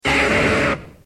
Cri de Reptincel dans Pokémon X et Y.